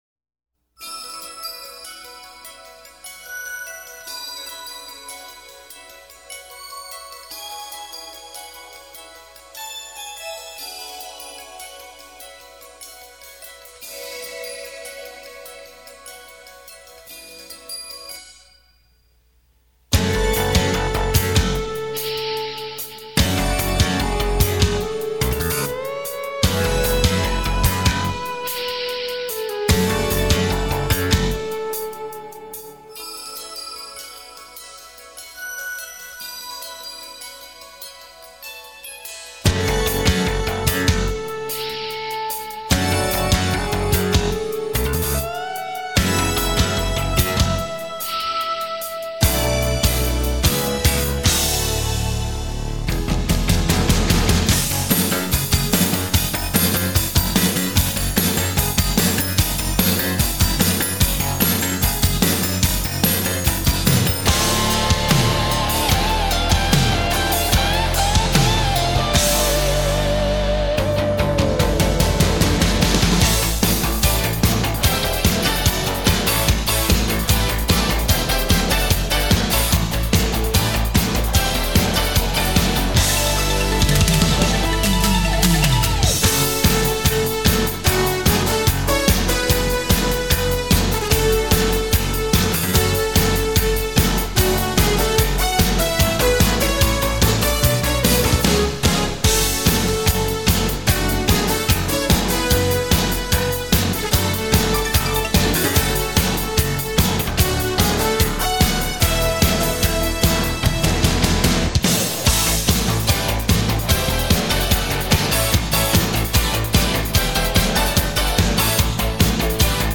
Esta canción es instrumental, y no tiene letra.